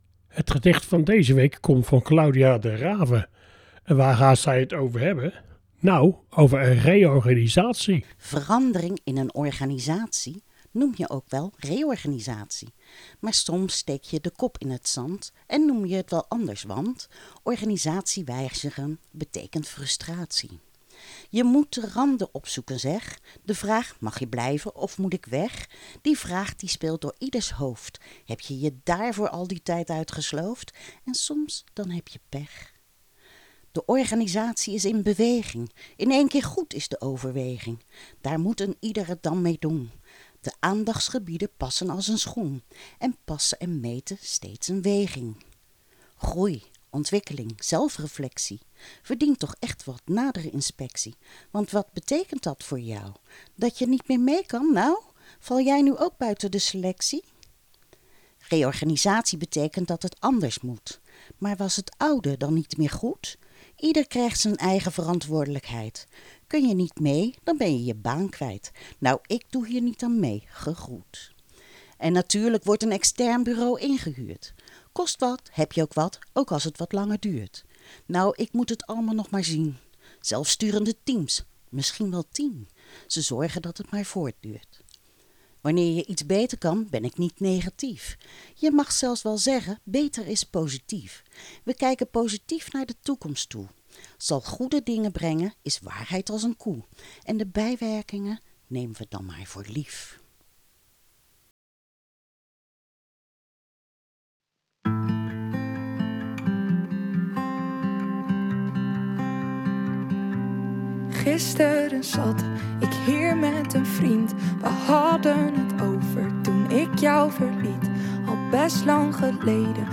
"Reorganisatie"�is�een�gedicht�geschrevenen�voorgedragen